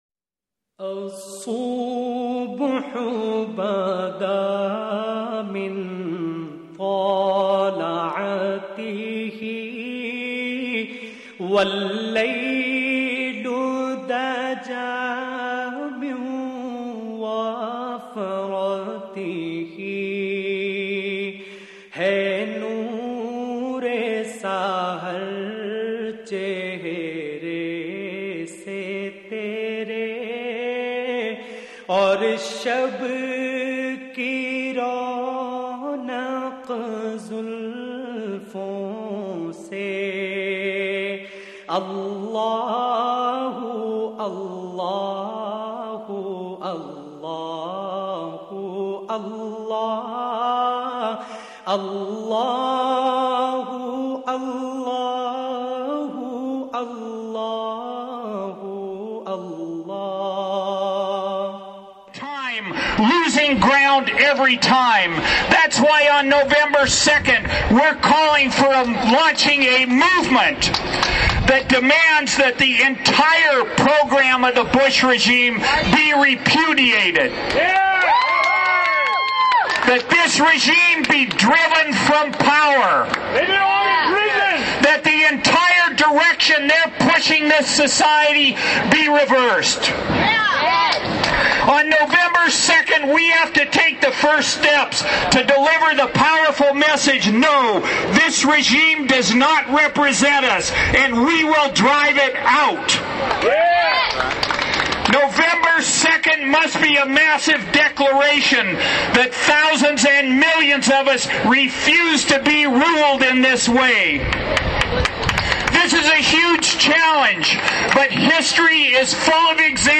Rally in San Francisco in solidarity with Cindy Sheehan
Audio from San Francisco rally in support of Cindy Sheehan